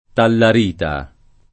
Tallarita [ tallar & ta ] cogn.